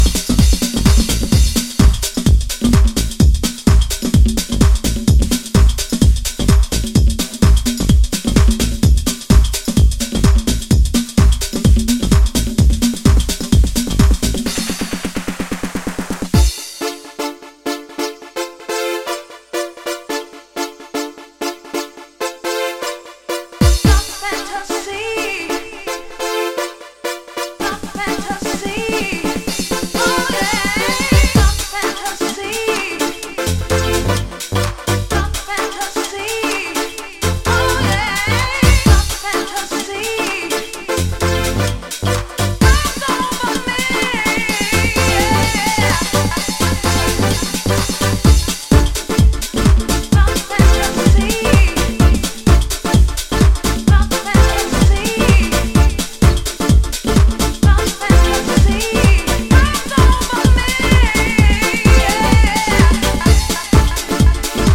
- увеличенная амплитуда: